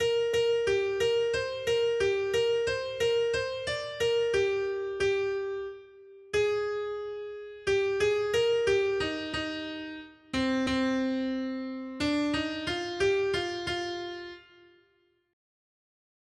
Noty Štítky, zpěvníky ol233.pdf responsoriální žalm Žaltář (Olejník) 233 Skrýt akordy R: Bože, ve své dobrotě ses postaral o chudáka. 1.